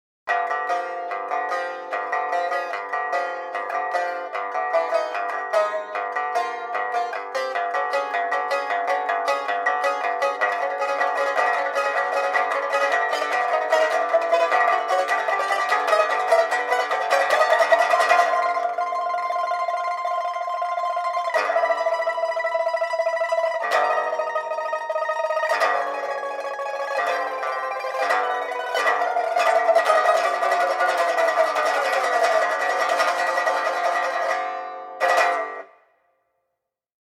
pipa